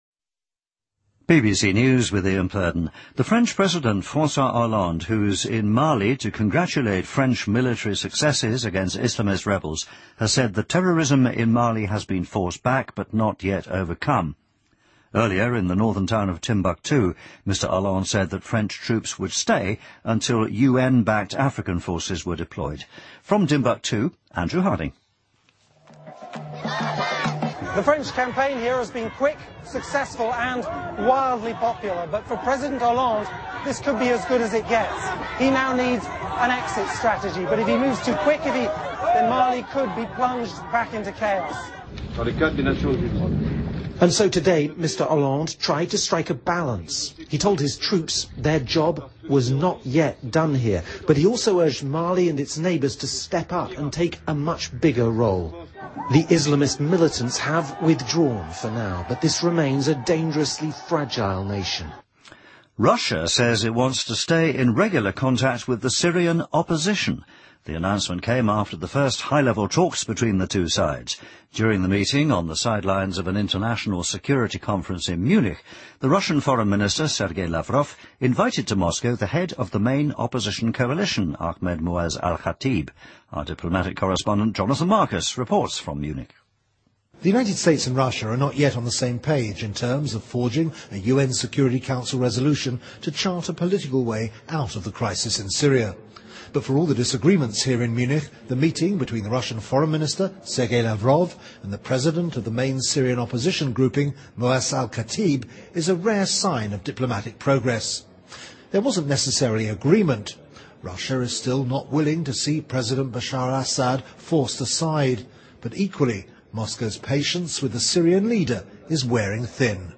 BBC news,2013-02-03